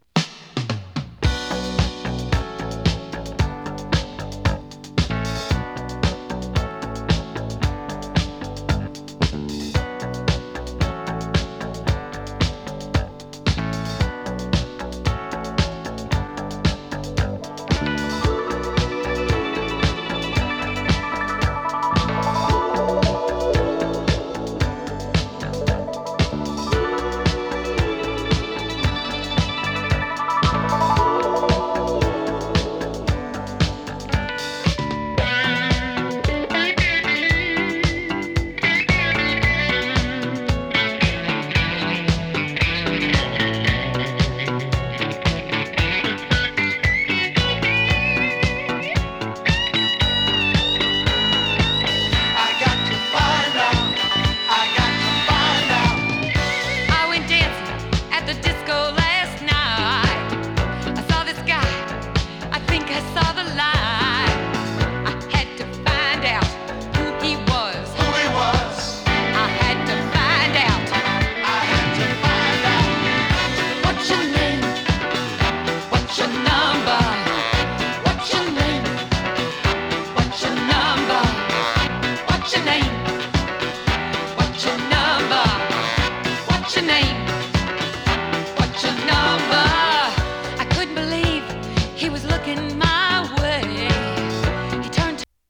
込み上げ系ガラージュ
徐々に熱を高めて行く展開も見事な込み上げ系ガラージュ・クラシック！